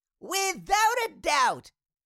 Cartoon Little Child, Voice, Without A Doubt Sound Effect Download | Gfx Sounds
Cartoon-little-child-voice-without-a-doubt.mp3